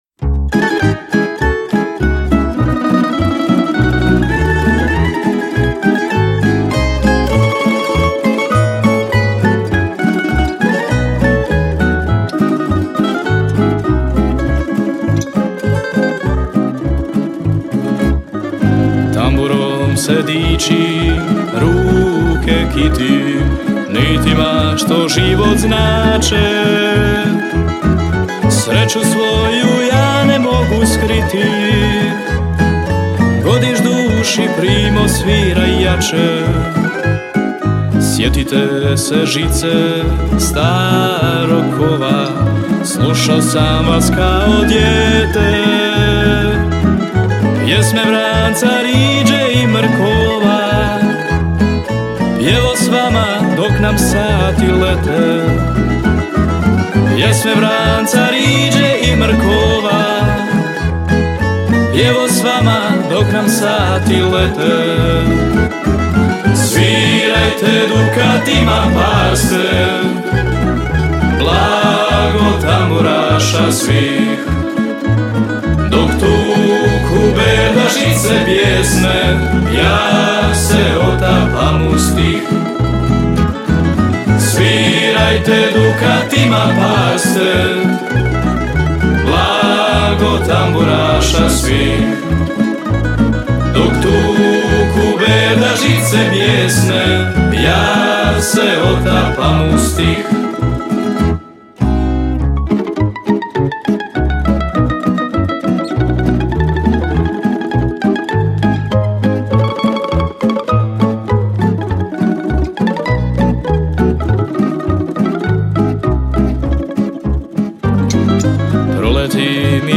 37. Festival pjevača amatera
Zvuci tamburice do kasnih noćnih sati odzvanjali su prepunom dvoranom vatrogasnog doma u Kaptolu.